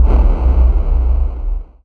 Techmino/media/effect/chiptune/enter.ogg at a07d57cf71d0faeba38dbe13a74a3f8ce4bac07f